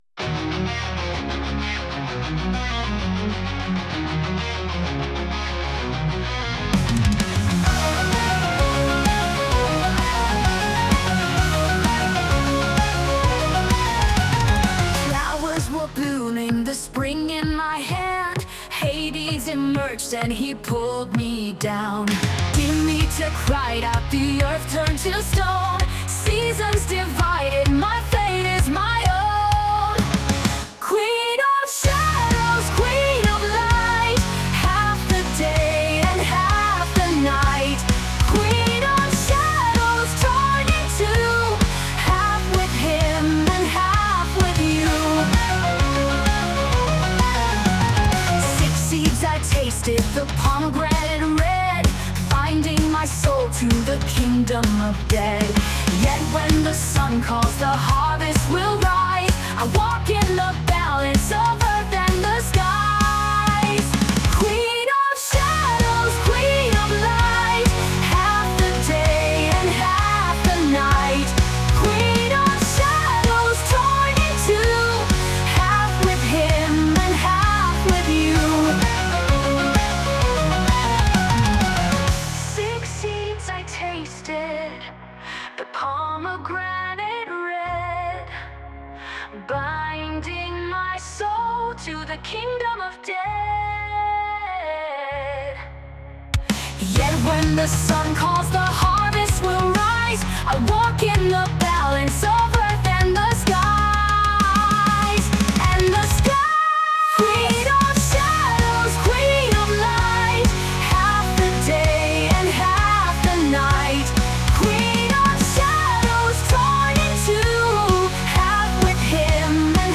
mit ChatGPT getextet und mit SUNO vertont.